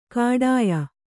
♪ kāḍāya